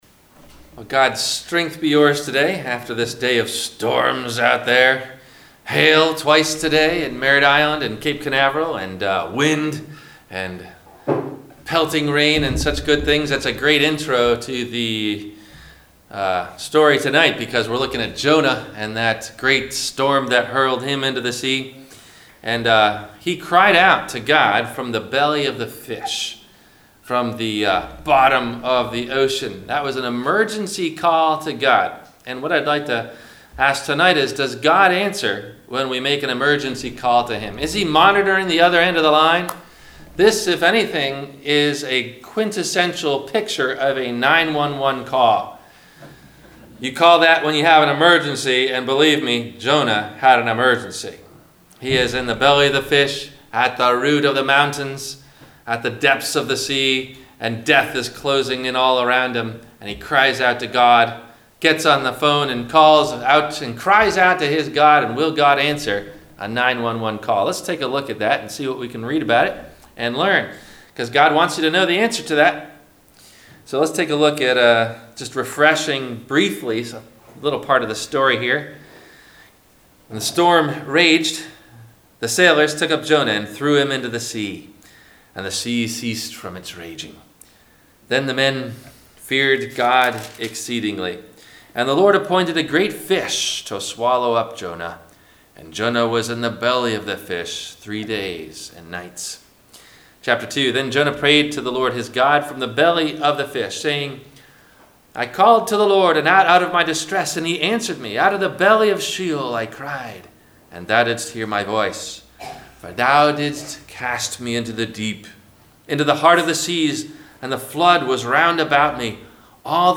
- Sermon - Wed Lent - March 27 2019 - Christ Lutheran Cape Canaveral